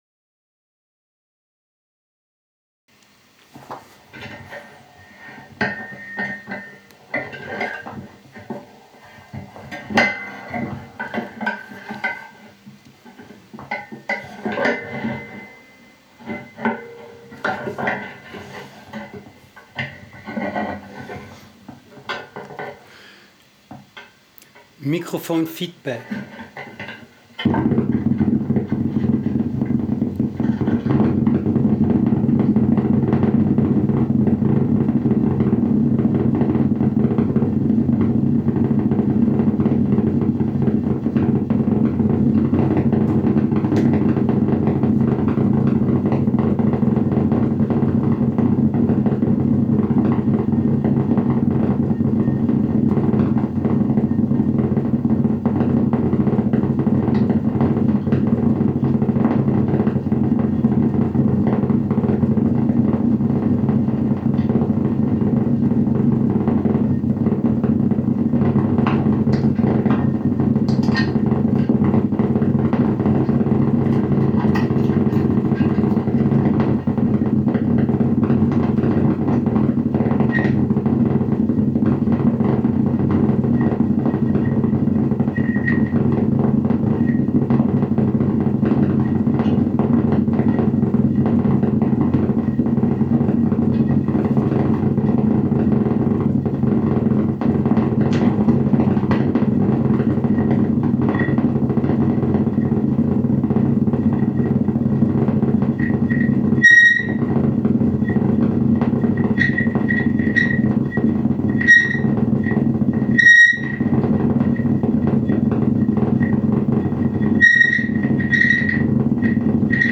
2016 Kontaktmikro Feedback (Video)
Video Soundtrack (mono, 16 Bit, aufgenommen mit Audacity)